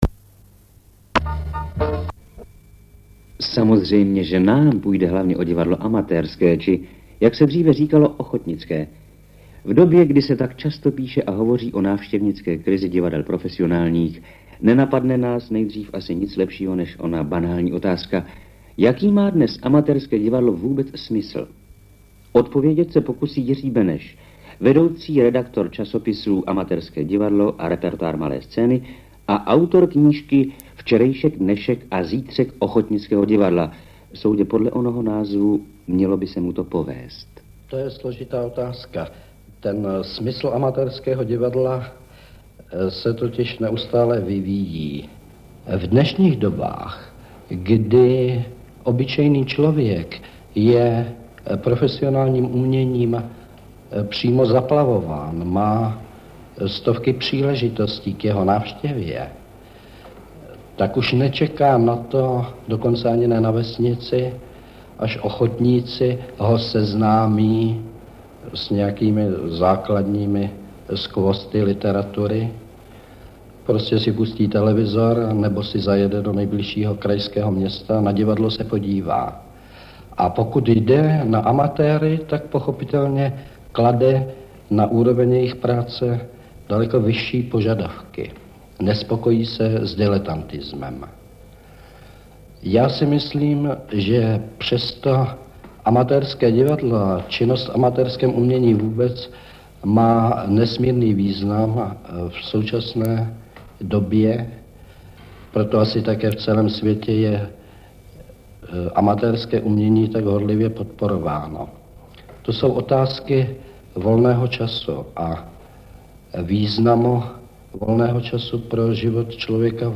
Pořad byl s největší pravděpodobností věnován VII. ročníku Divadelního máje – Národní přehlídce amatérských divadelních souborů v Krnově. Záznam – fragment relace pochází z velmi poničené magnetofonové pásky z roku 1965.